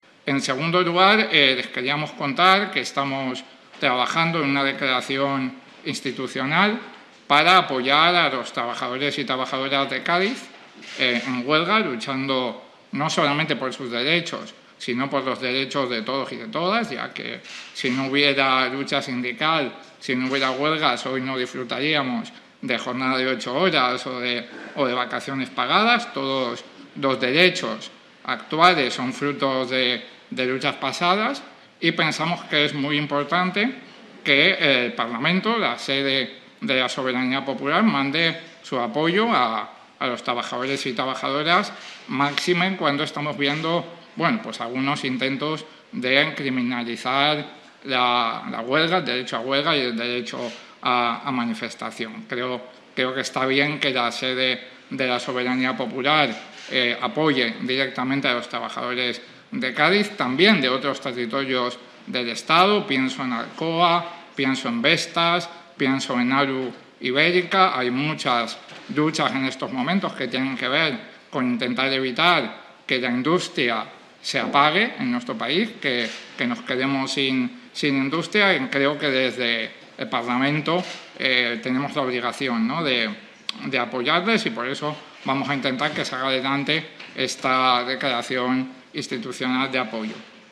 En este sentido, Echenique ha expresado en rueda de prensa en el Congreso de los Diputados que los trabajadores, en huelga indefinida desde el martes 16 de noviembre por la mejora salarial en la renovación del convenio colectivo que se negocia entre empresarios y sindicatos, “no sólo luchan por sus derechos, sino por los de todos y todas”.
Audio-de-Pablo-Echenique.mp3